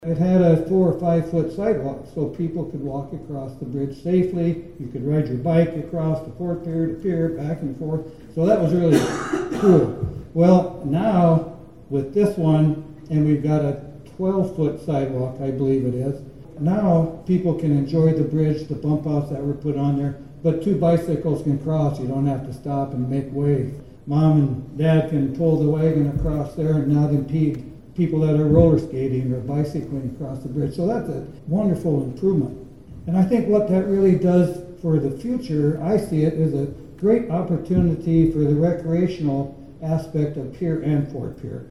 Various local, state and federal dignitaries gathered on Tuesday (March 25, 2025) to hold the official ribbon cutting ceremony for the new Lieutenant Commander John C. Waldron Memorial Bridge over the Missouri River between Fort Pierre and Pierre.